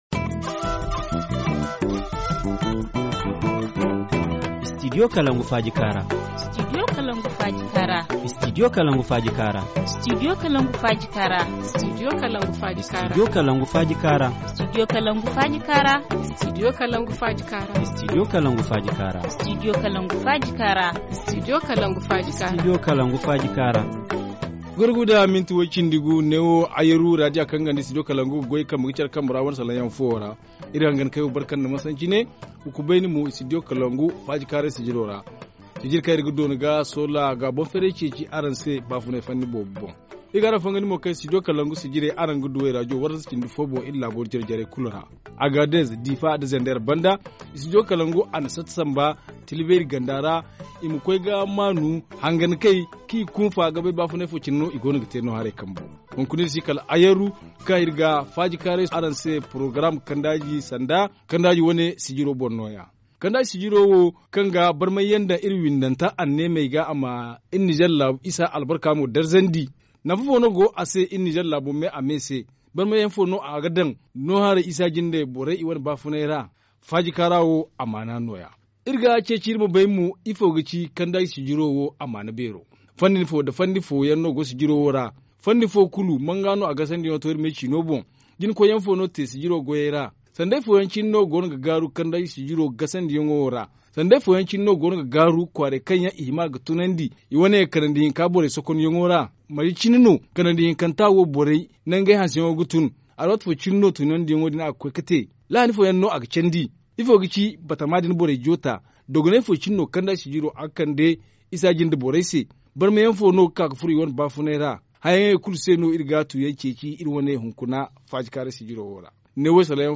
Forum en Zarma